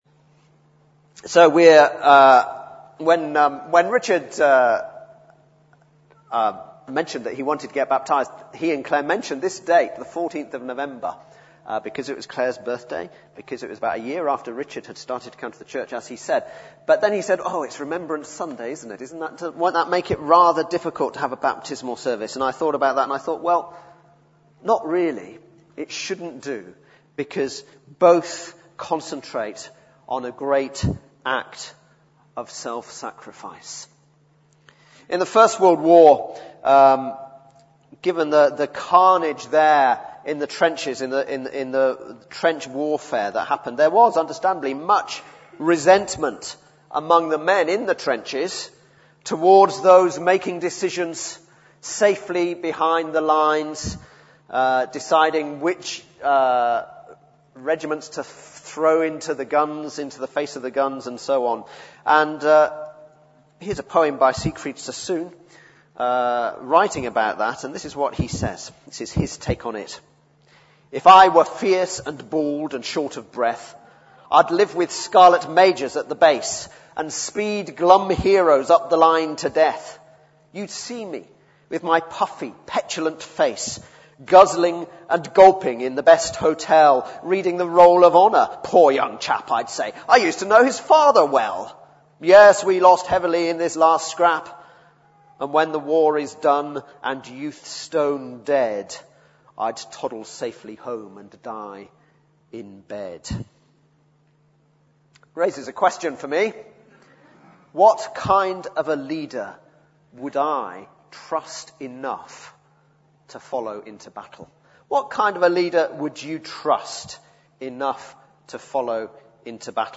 Baptismal Service – November 2010